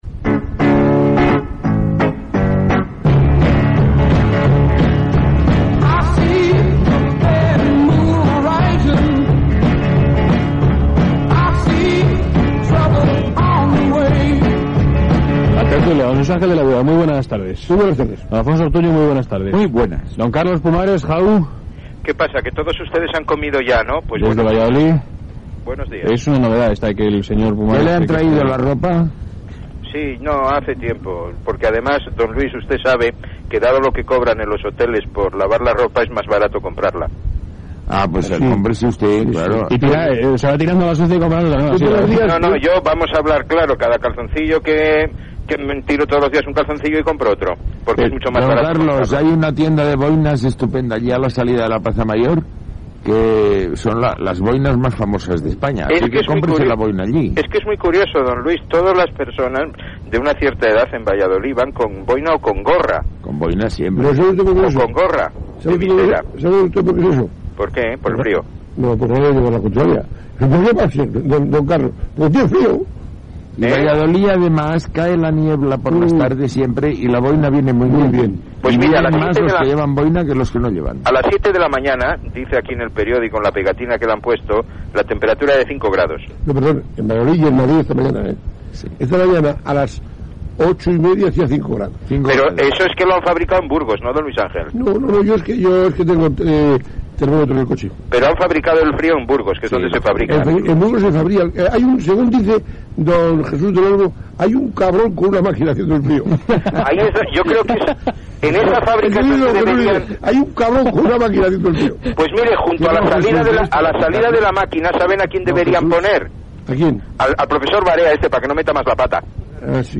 Tertulia
Valladolid, el fred, beure llet, etc. Publicitat.
Gènere radiofònic Entreteniment